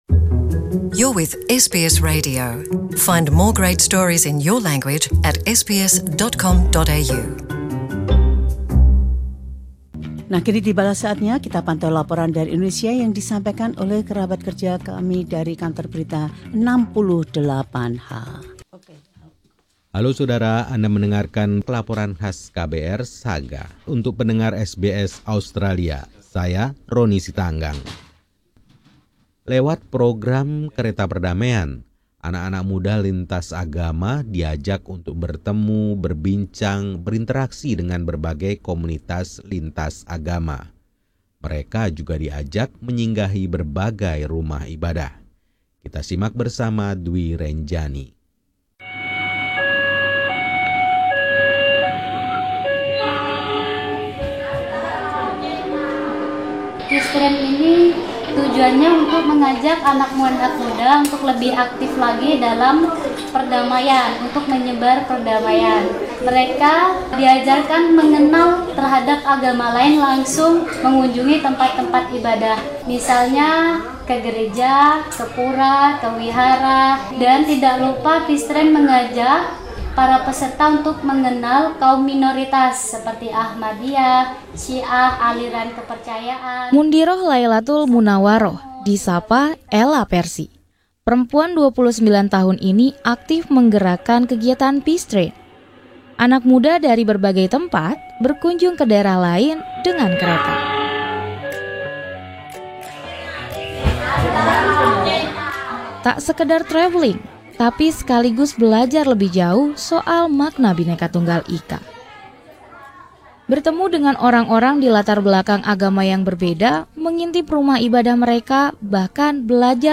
Tim KBR 68H melaporkan sebuah proyek yang memberikan pemahaman akan keragaman populasi Indonesia.